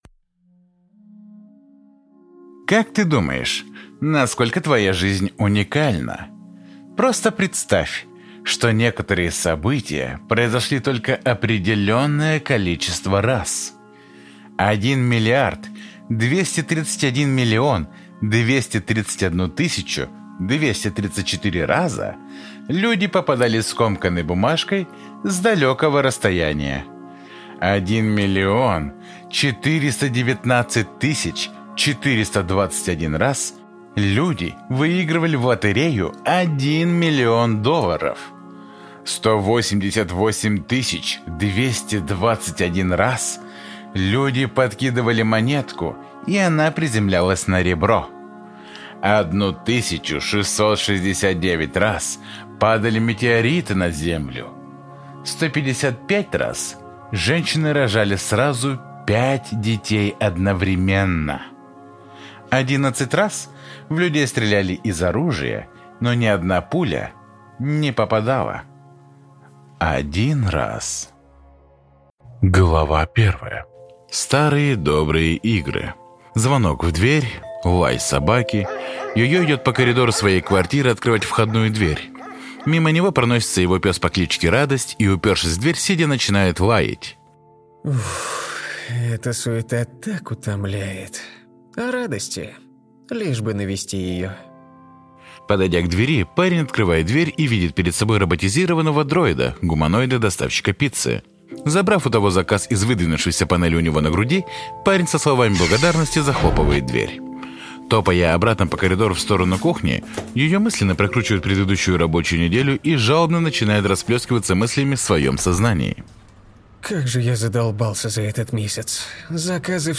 Аудиокнига Start over?
Прослушать и бесплатно скачать фрагмент аудиокниги